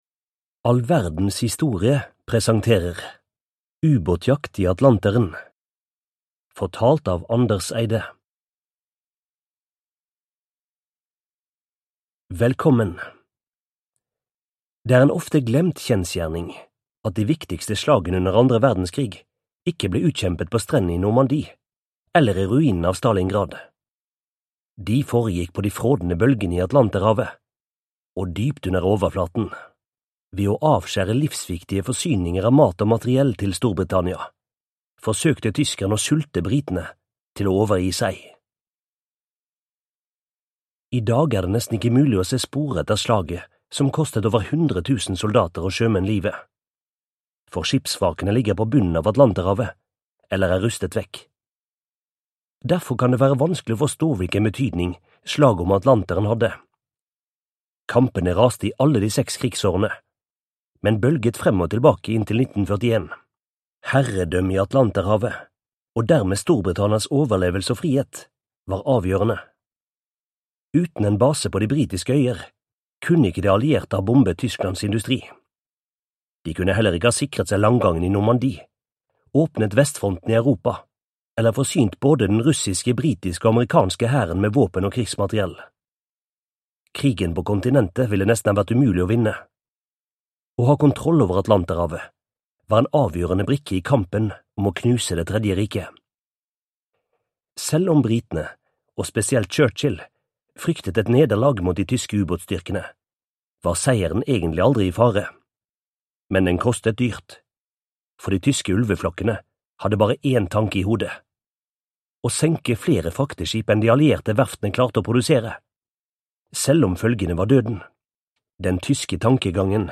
Uba°tjakt i Atlanteren (ljudbok) av All Verdens Historie